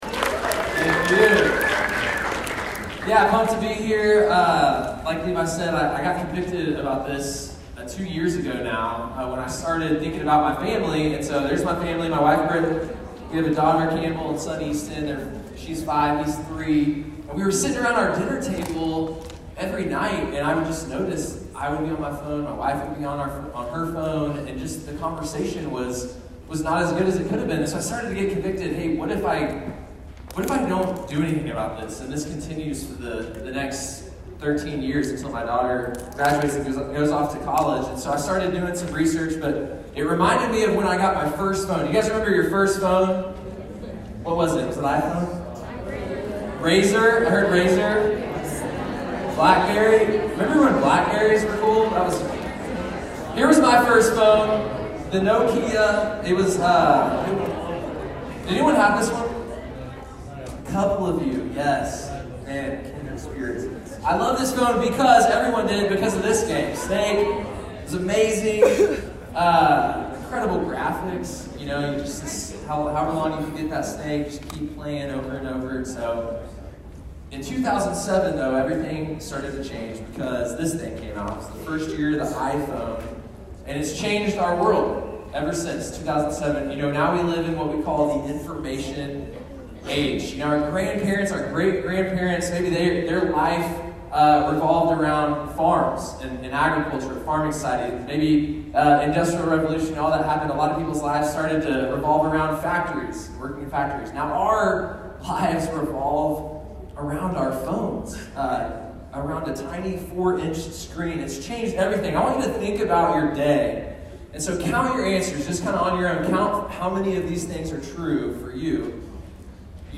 SMC 2020 Breakout Audio